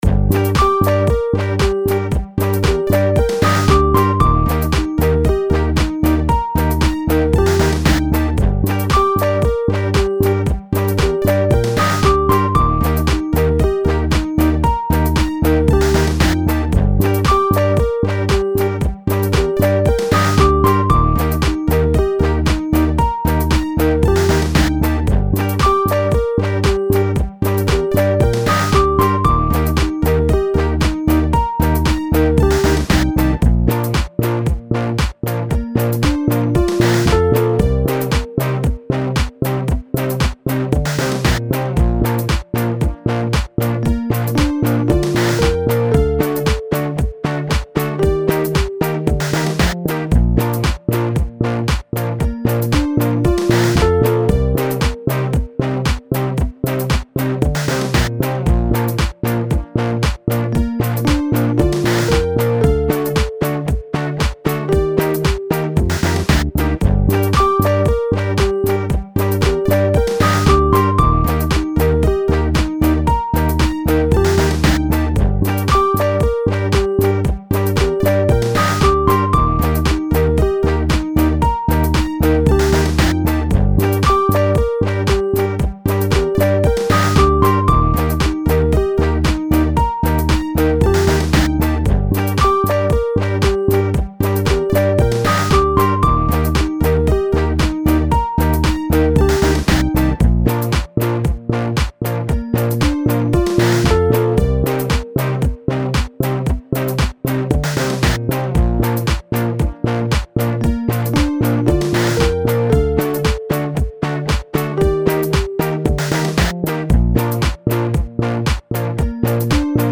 Nothing special going on here, just a few synths, a simple drum beat, and my guitar.
mw-summer-sketch.mp3